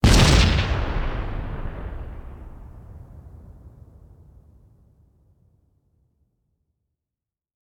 explode2.mp3